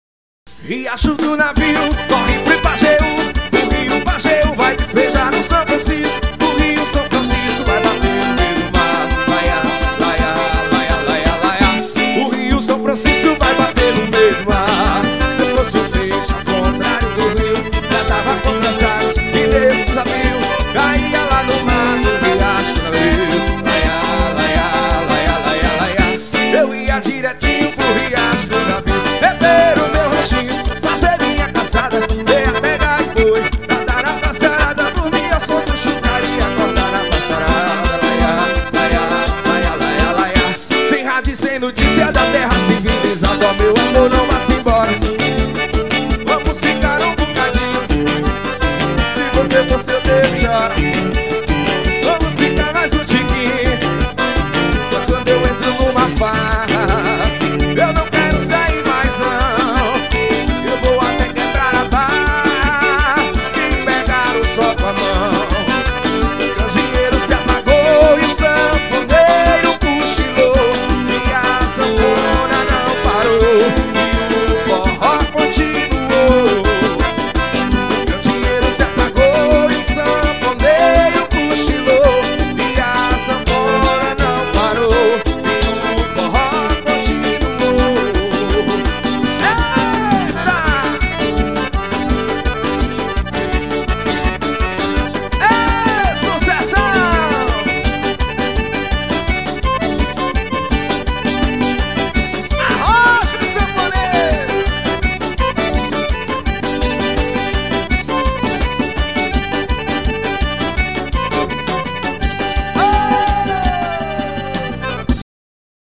Forró.